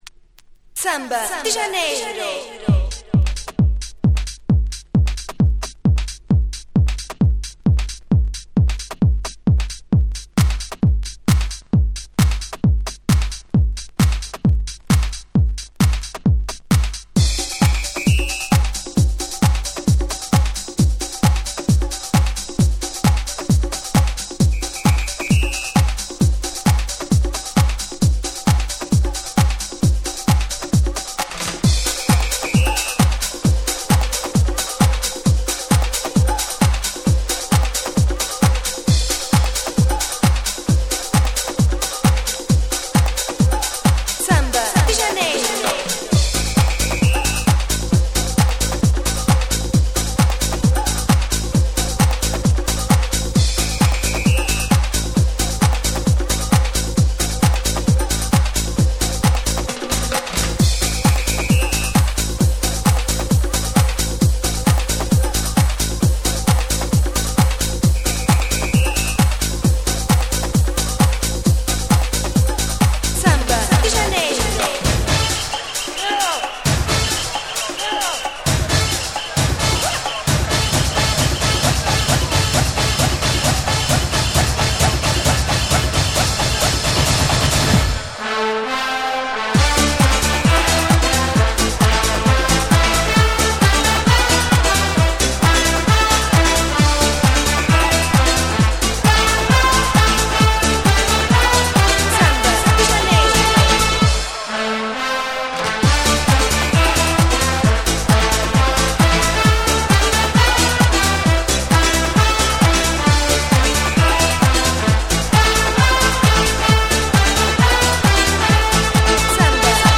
97' Nice Cover !!
オリジナルとほぼ変わりません(笑)